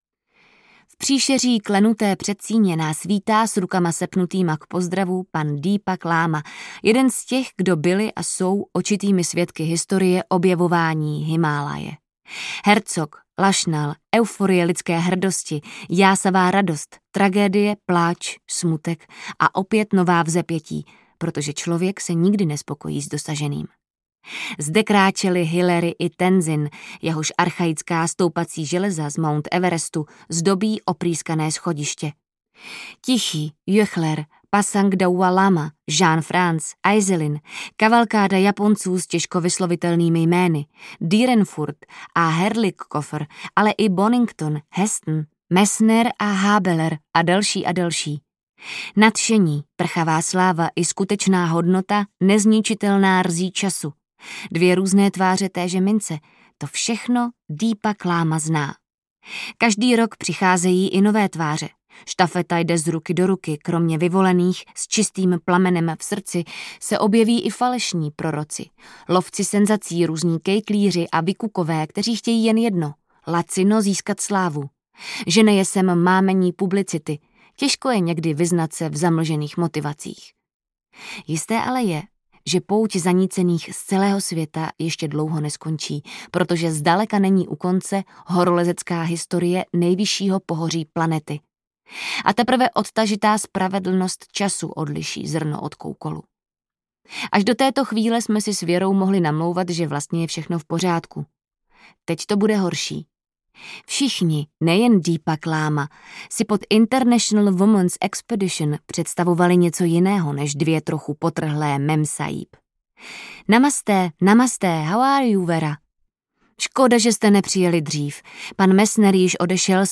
Tyrkysová hora audiokniha
Ukázka z knihy
• InterpretAnita Krausová